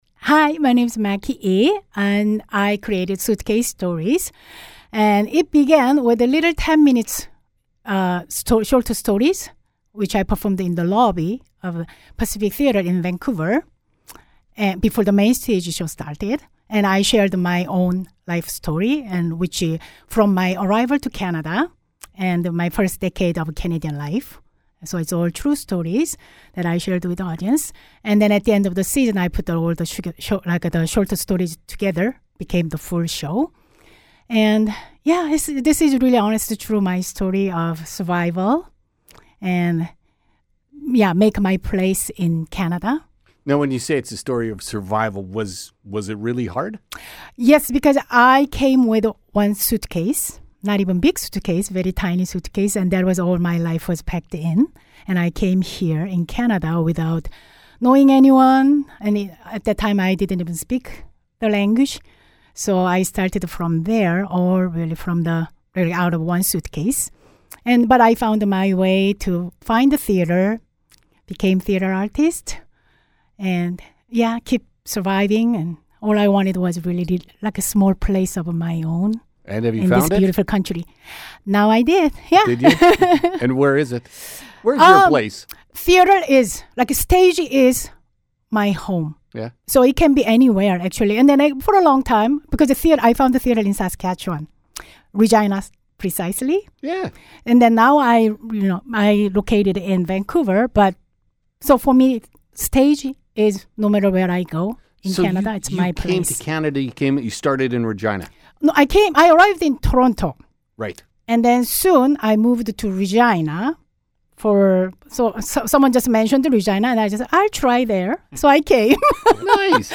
suitcase-stories-interview.mp3